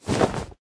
minecraft / sounds / mob / llama / swag.ogg